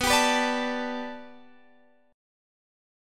Bm7#5 chord